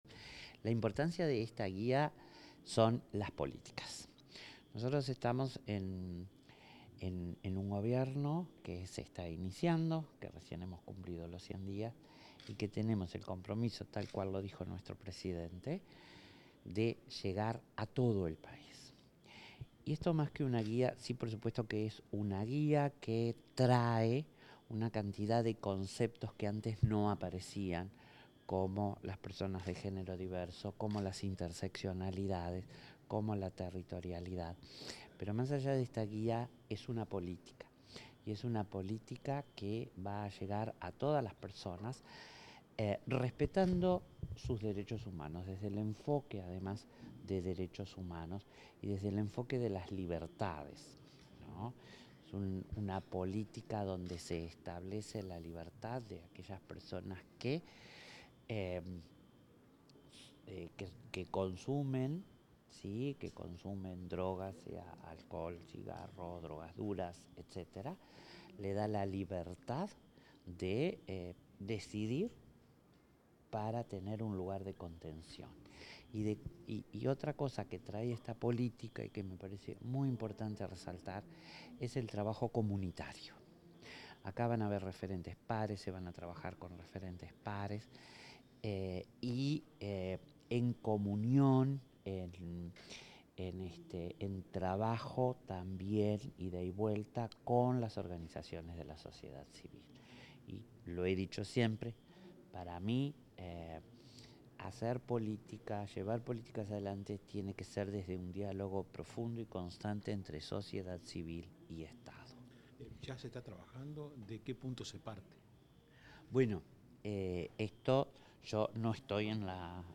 Declaraciones de la secretaria de Derechos Humanos, Collette Spinetti
La secretaria de Derechos Humanos de la Presidencia de la República, Collette Spinetti, dialogó con la prensa, luego de la presentación de la guía